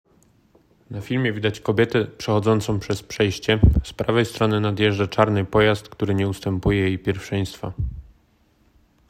Nagranie audio audiodeskrypcja przejście dla pieszych